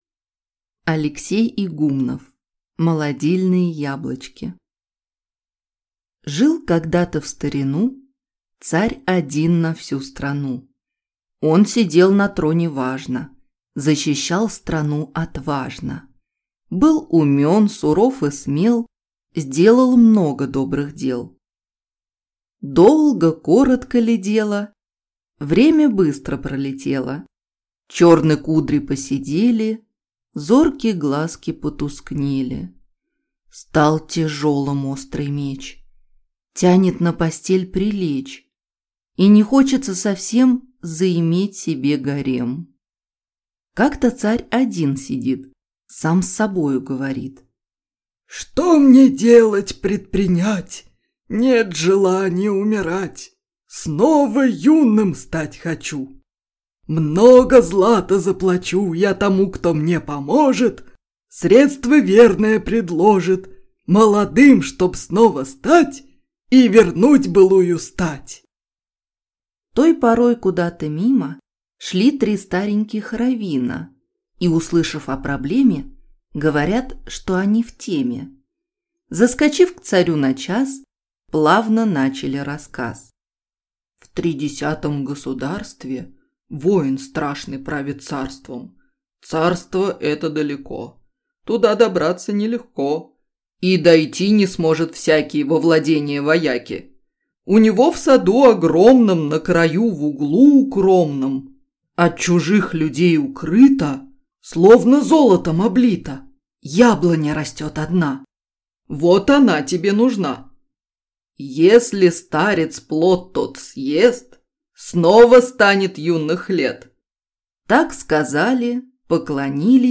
Аудиокнига Молодильные яблочки | Библиотека аудиокниг